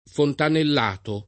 [ fontanell # to ]